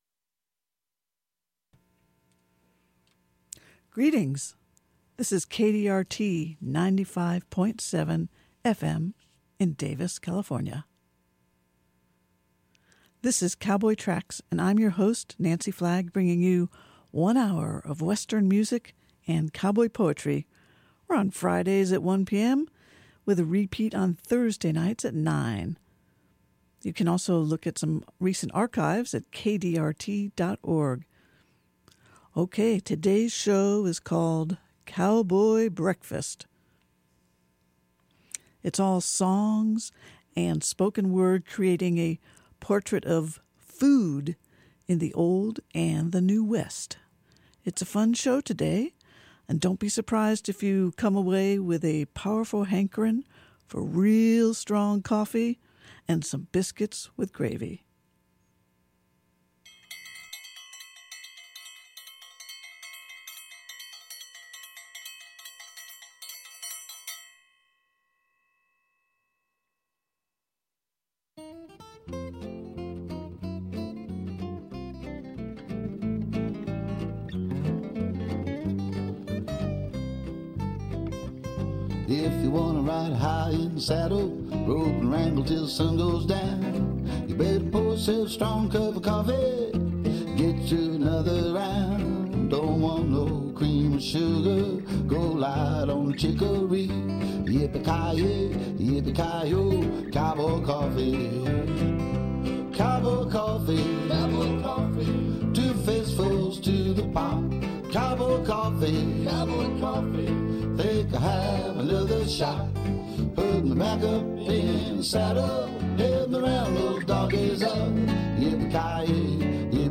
Something deep in your soul will resonate to stories from the early wranglers, Mexican vaqueros, Old West adventurers, Silver Screen buckaroos, Western Swing bands, contemporary real cowboys, and all who appreciate wide open spaces, freedom, and the western lifestyle.